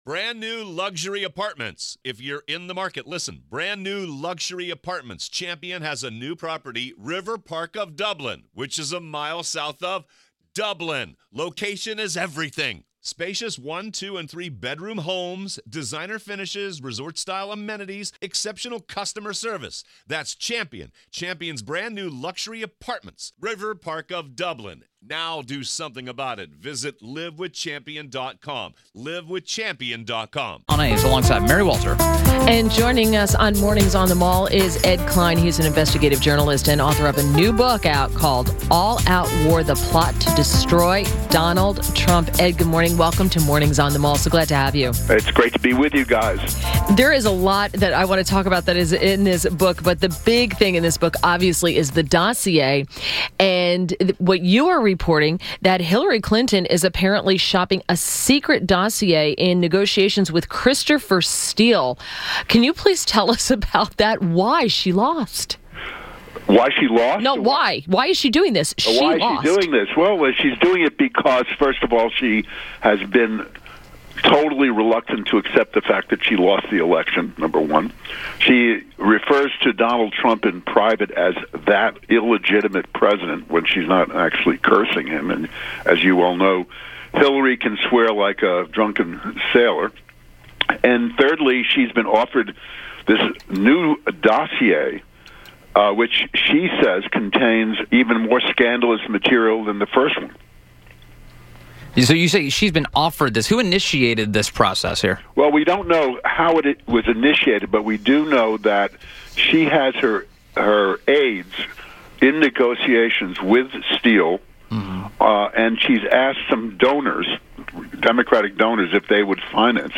WMAL Interview - STEVE MOORE - 11.09.17
INTERVIEW -- STEVE MOORE - economist at the Heritage Foundation